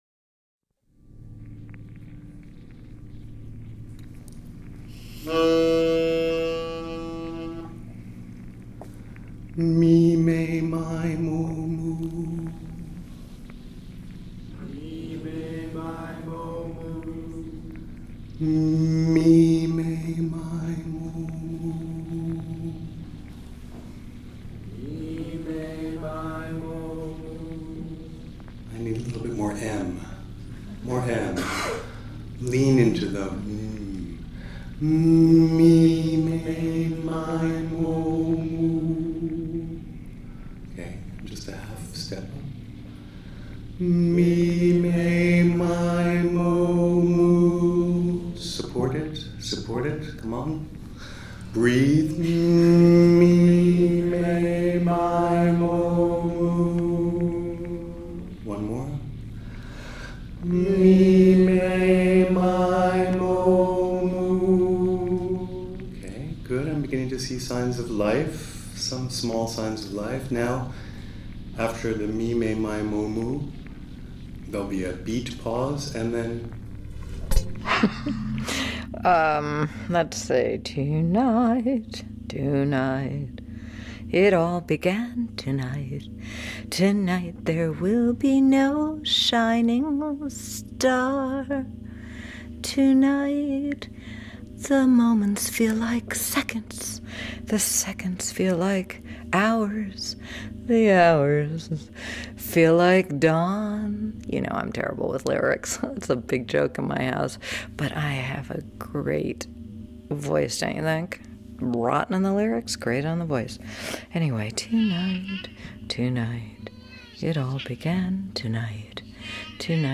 A FREELY ASSOCIATIVE DISORDER FOR WASHED UP VOICES